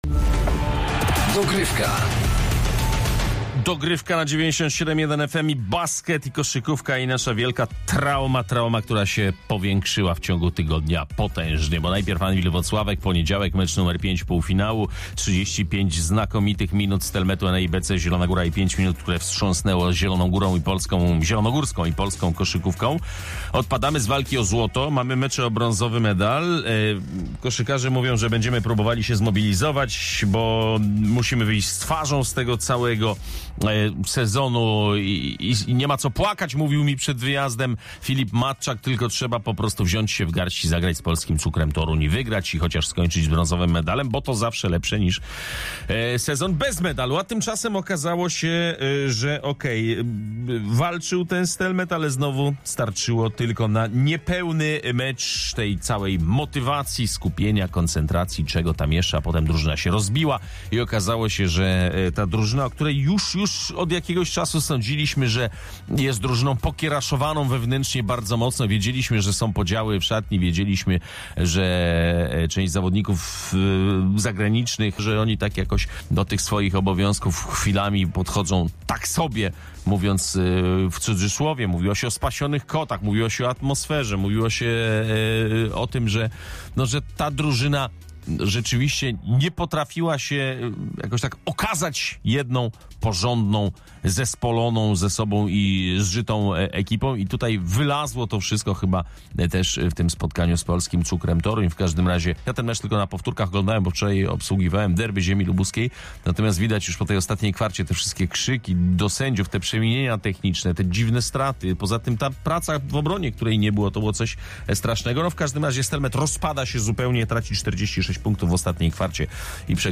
Głos zabierają tez kibice.